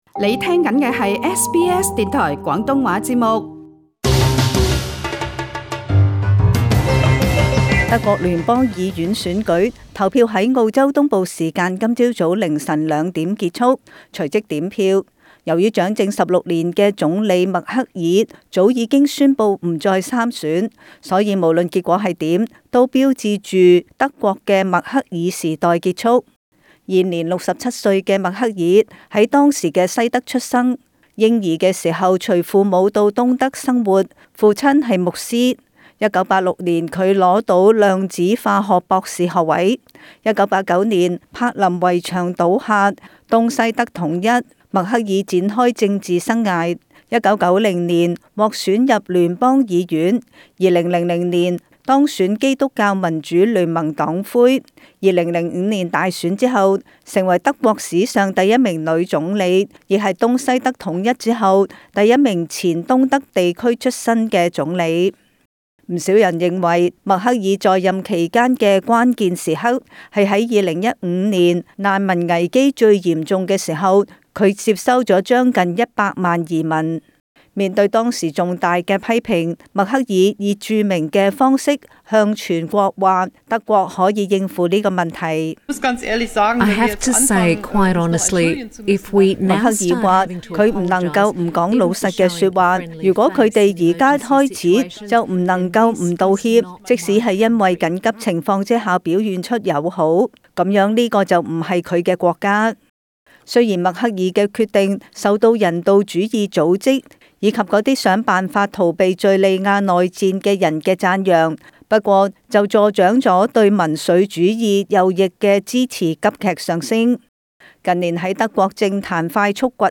時事報道